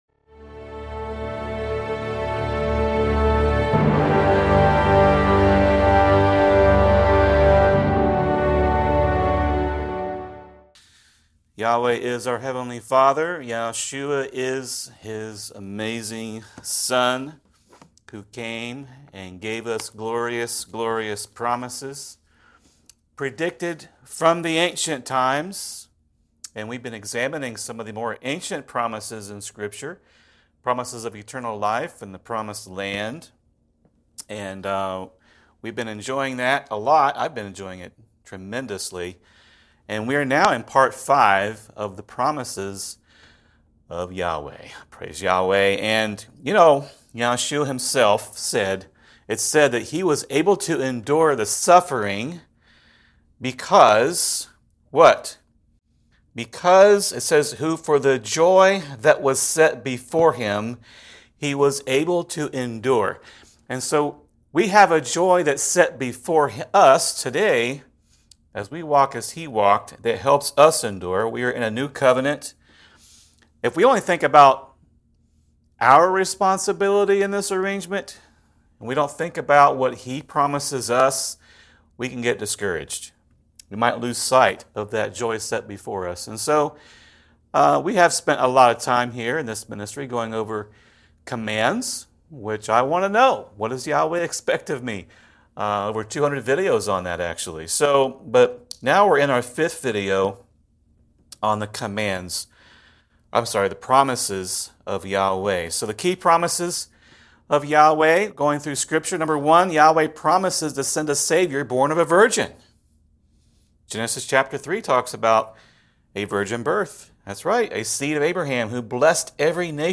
Video Transcript This is a direct transcript of a teaching that was presented via video.